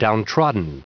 Prononciation du mot downtrodden en anglais (fichier audio)
Prononciation du mot : downtrodden